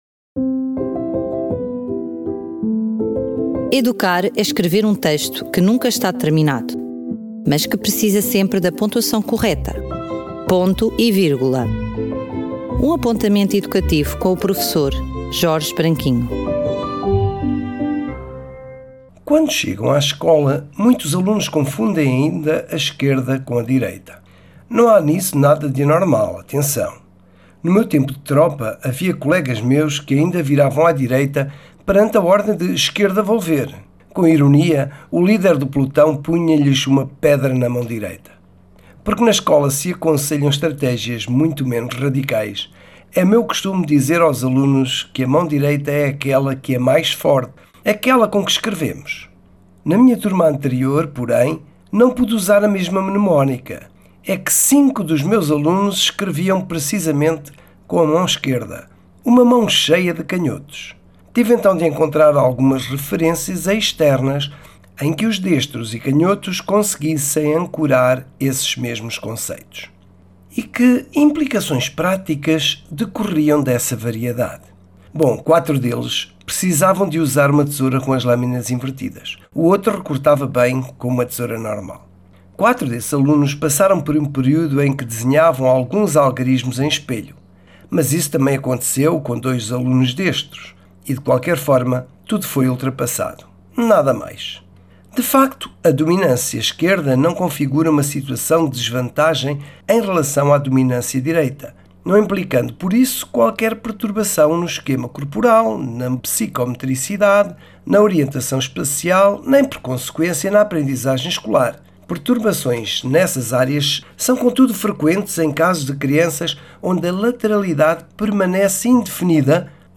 Rubrica semanal, apresentada na forma de um apontamento educativo e a partir da longa experiência de um professor, que aborda os desafios e os diferentes ângulos do universo da Educação.